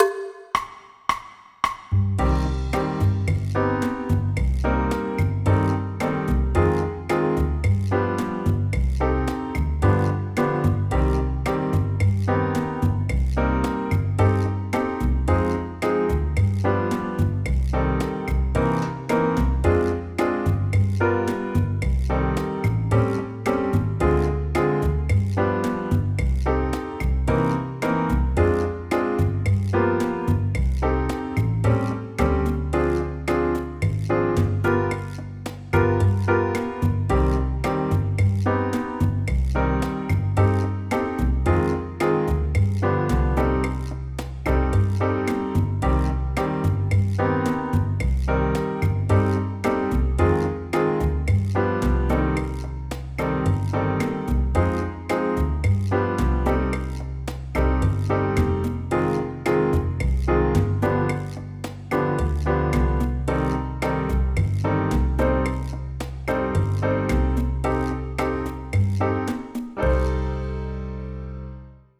Accompaniment Music Files